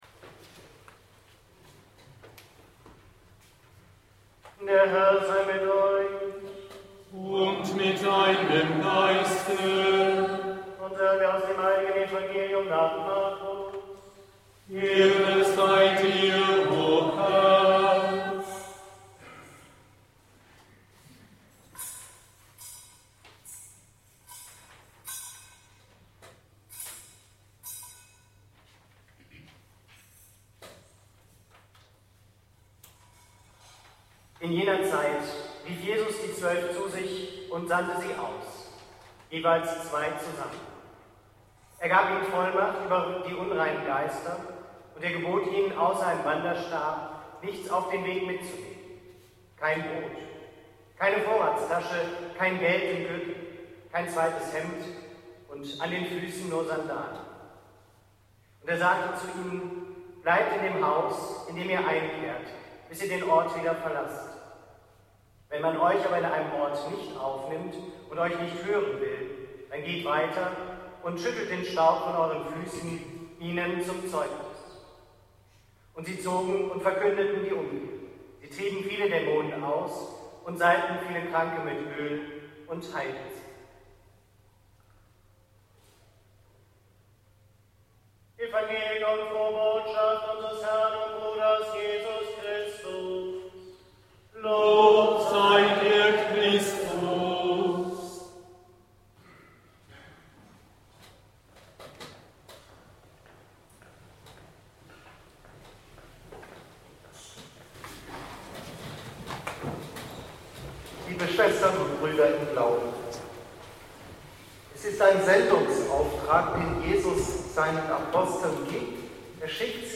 Mit einem schönen Gottesdienst und abwechslungsreicher musikalischer Unterstützung hat unser Weihbischof Wilfried Theising sowohl die Kita Heiliger Alexander und auch unser neues Christophorus-Haus am Brookweg eingeweiht.